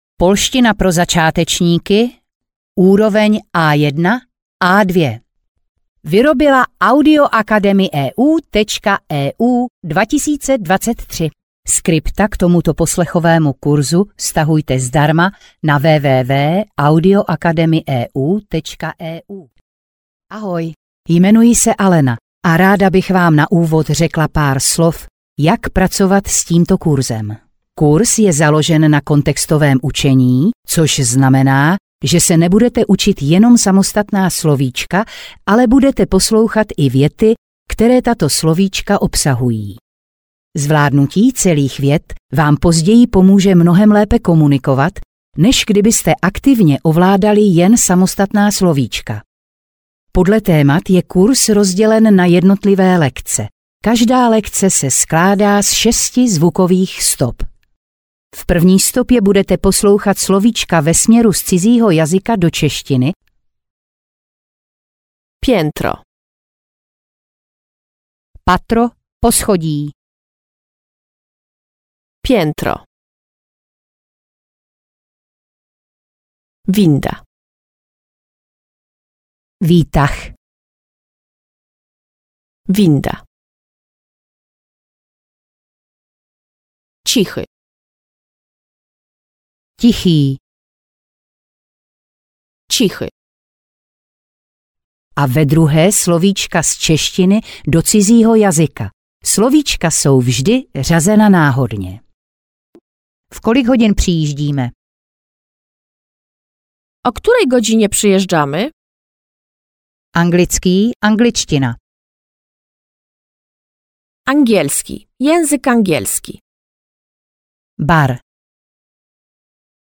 Audiokniha Polština pro začátečníky A1-A2. Děkujeme za zájem o poslechový kurz Polštiny pro začátečníky A1-A2.
Ukázka z knihy